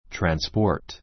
transport trænspɔ́ː r t ト ラン ス ポ ー ト 動詞 （乗客・貨物を） 輸送する, 運送する, 運ぶ ⦣ carry, take より堅 かた い語. trǽnspɔː r t ト ラ ン ス ポー ト （ ⦣ 動詞とのアクセントの位置の違 ちが いに注意） 名詞 主に英 （乗客・貨物の） 輸送, 運送; 輸送[交通]機関, 英話 交通手段 （ 米 transportation）